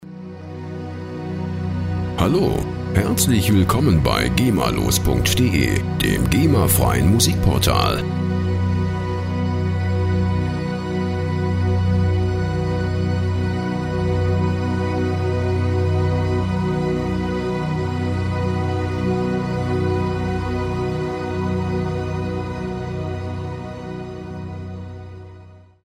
flächiger Synthesizer-Sound